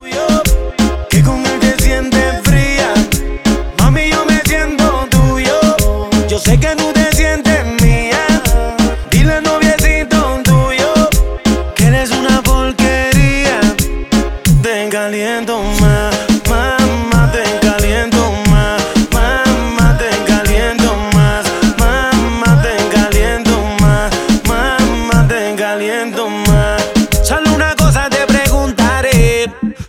• Latin Urban